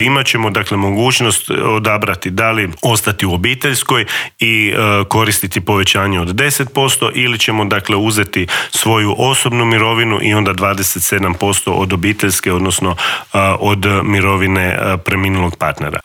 ZAGREB - Gostujući u Intervjuu tjedna Media servisa ministar rada, mirovinskoga sustava, obitelji i socijalne politike Marin Piletić otkrio je detalje pregovora sa sindikatima oko povišica, ali se osvrnuo i na najavu zabrane rada nedjeljom, kritikama na novi Zakon o radu, kao i o novostima koje stupaju na snagu 1. siječnja, a tiču se minimalne plaće i mirovina.